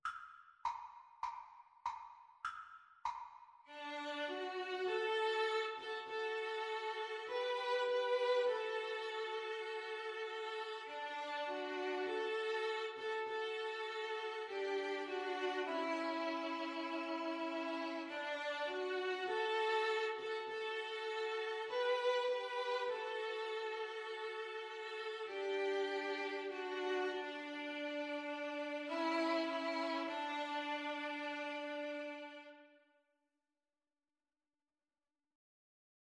Free Sheet music for String trio
D major (Sounding Pitch) (View more D major Music for String trio )
Cantabile =c.100
4/4 (View more 4/4 Music)